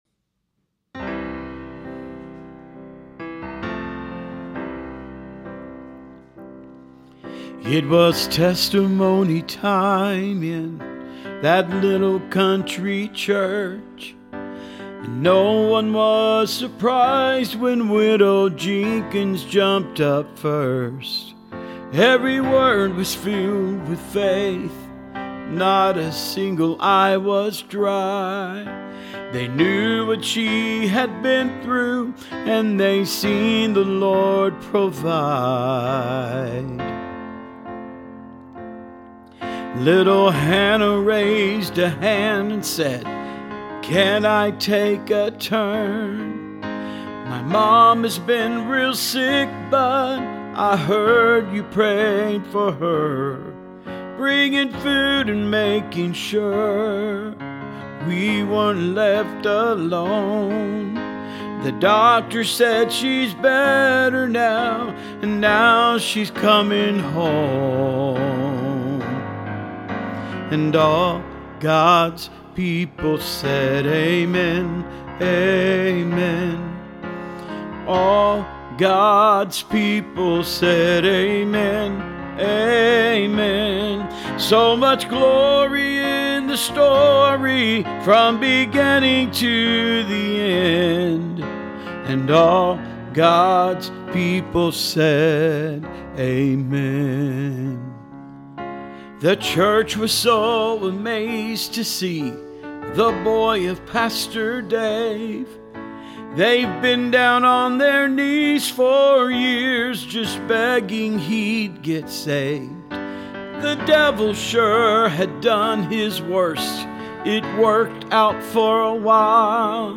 Solo Demo